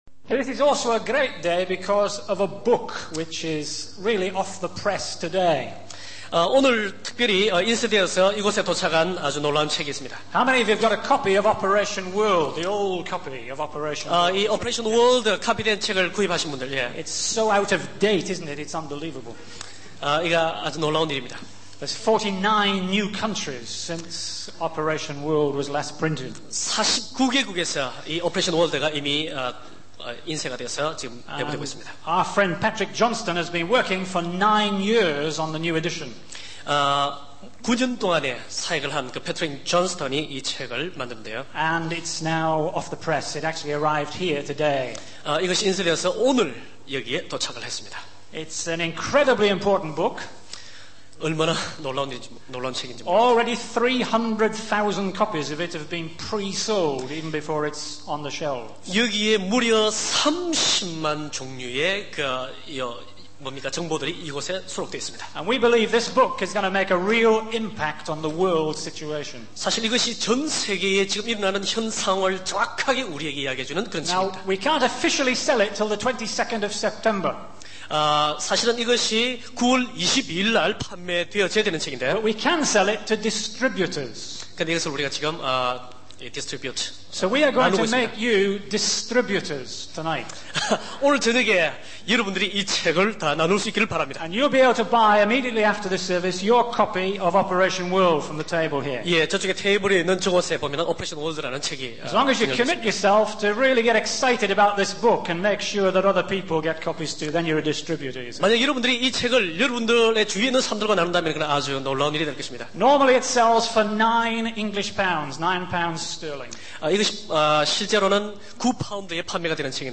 In this sermon, the speaker begins by reading the first five verses of Psalm 103. He then poses the question of whether it is possible for finite human beings to truly know and comprehend the infinite God. He references Job's friend and Paul's words in Romans 11 to emphasize the unfathomable wisdom and knowledge of God.